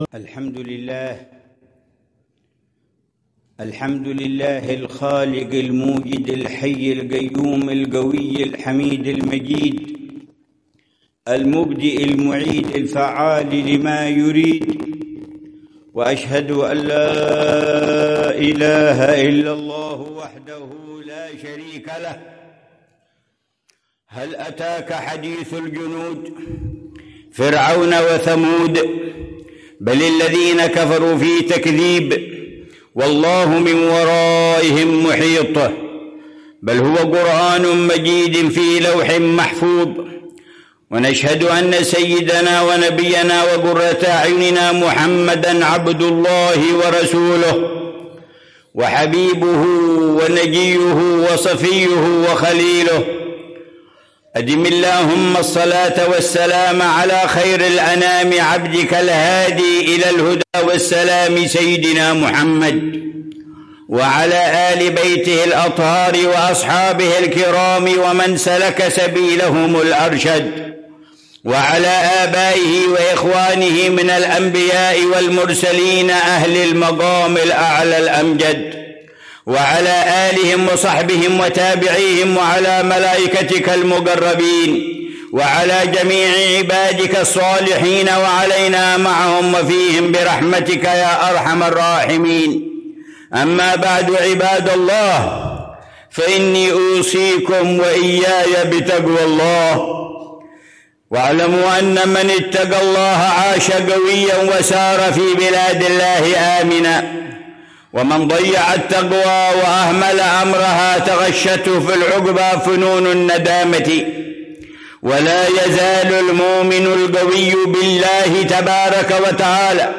خطبة الجمعة للعلامة الحبيب عمر بن محمد بن حفيظ في مسجد الملك عبدالله، في عمّان، الأردن، 2 جمادى الأولى 1447هـ بعنوان: